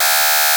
Ringing10.wav